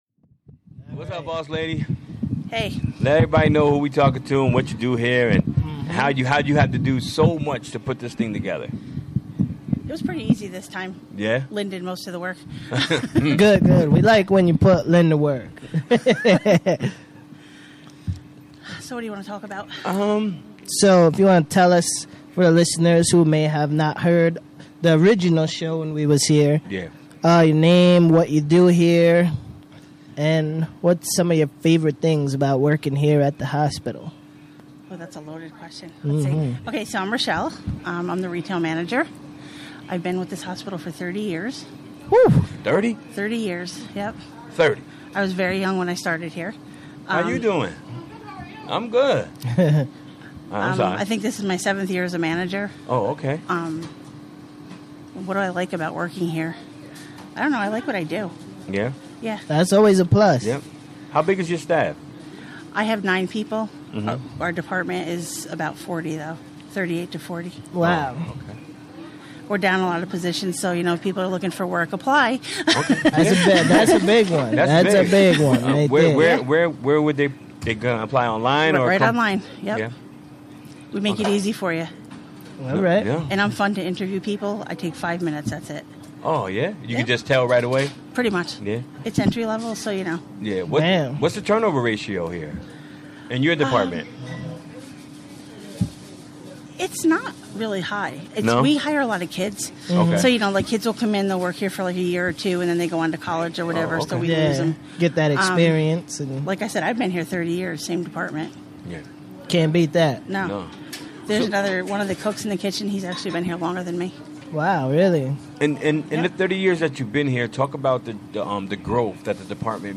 Live from Columbia Memorial Hospital: Jan 24, 2018: 4pm - 6pm